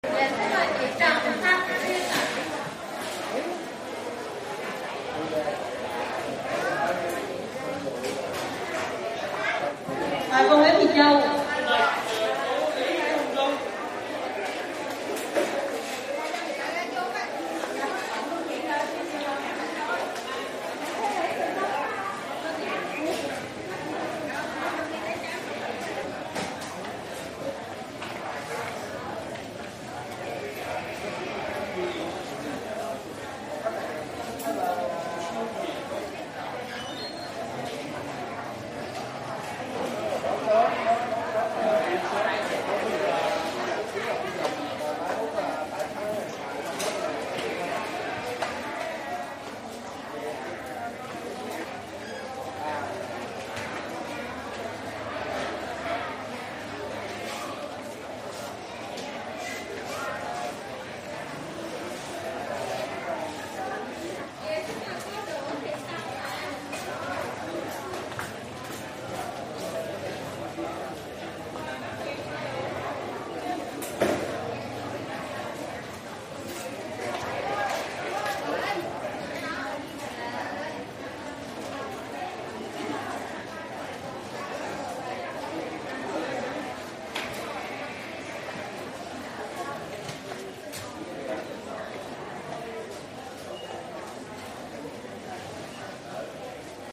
Tiếng Ồn ào ở Bệnh viện, Phòng khám bệnh
Thể loại: Tiếng con người
Description: Tiếng Ồn ào ở Bệnh viện, tiếng nhiều người nói chuyện xì xào, bàn tán, đông đúc, đông người, tiếng đọc số thứ tự khám bệnh thường nghe thấy ở bệnh viện, Phòng khám bệnh...
tieng-on-ao-o-benh-vien-phong-kham-benh-www_tiengdong_com.mp3